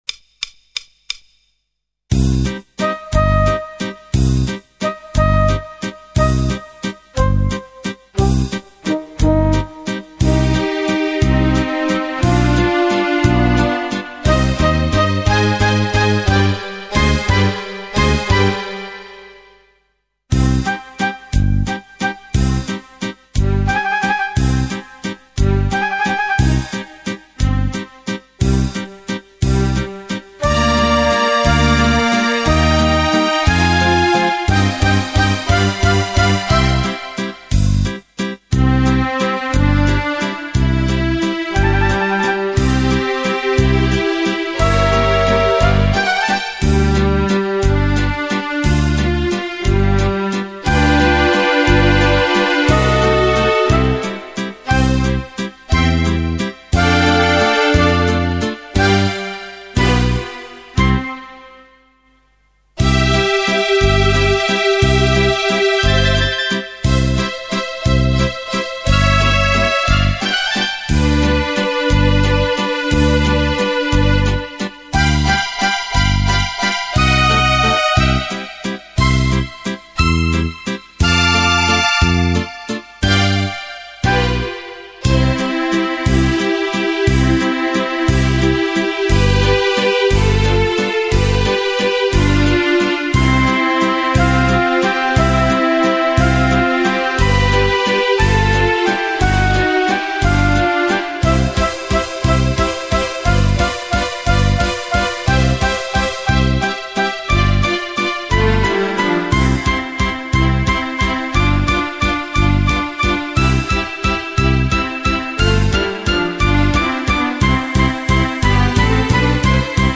Valzer. Fisarmonica